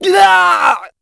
hotshot_die_04.wav